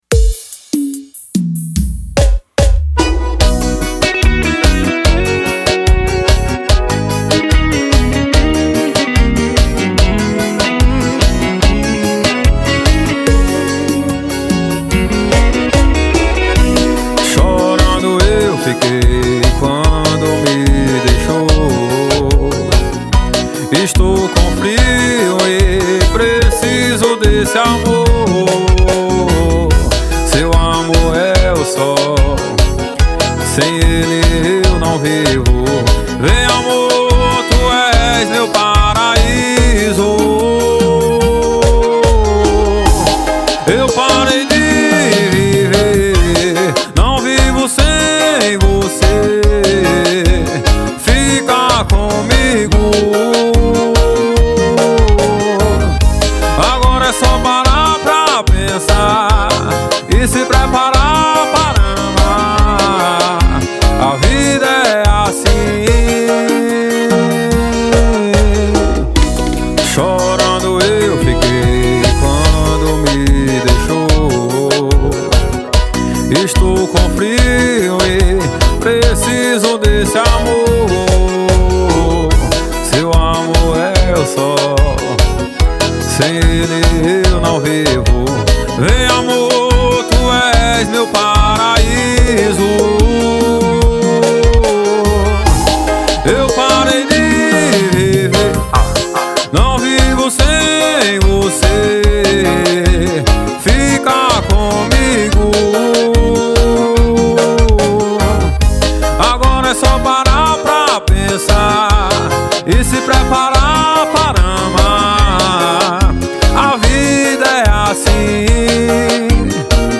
2024-12-30 11:04:18 Gênero: Forró Views